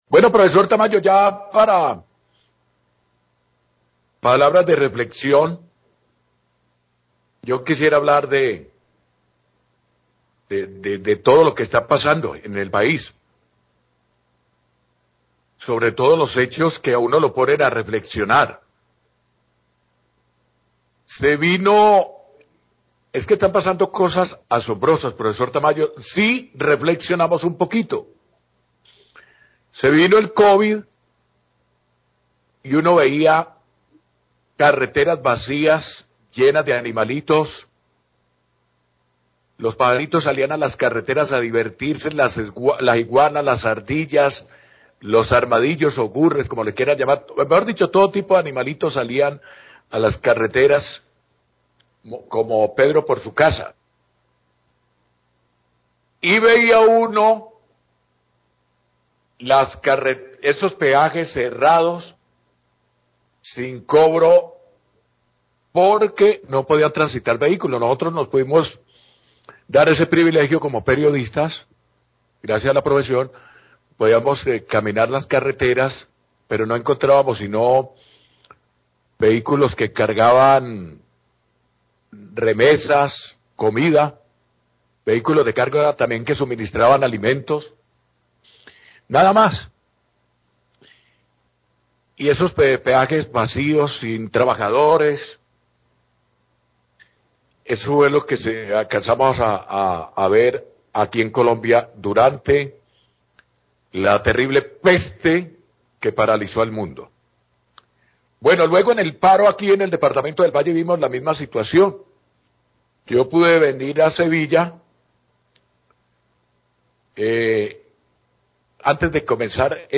Radio
Periodista hace un recuento de las situaciones en los últimos años donde ha tenido que ver peajes cerrados. Menciona la época de pandemia del Covid 19, cuando el paro nacional y, ahora, por la caída del puente del Alambrado. Luego pasa a criticar la avaricia de los concesonarios viales , dueños de los peajes y menciona el peaje de Cerritos.